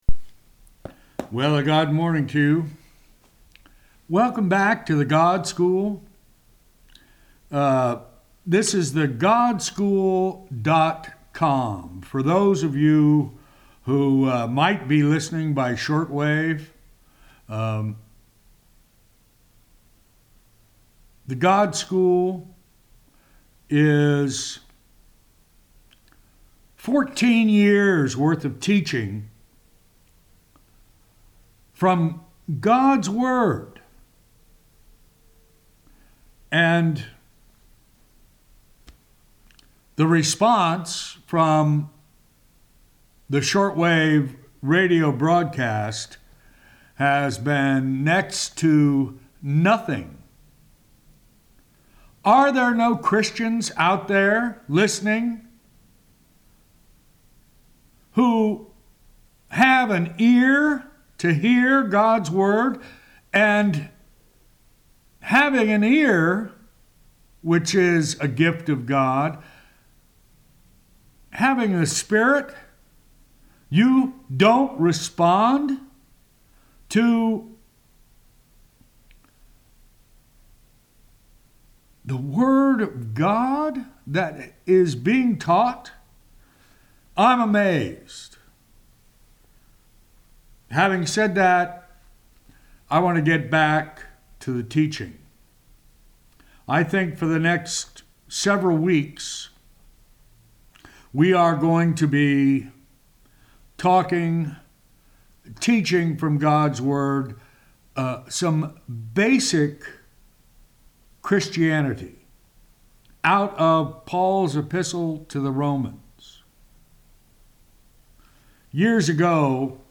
Weekly Teaching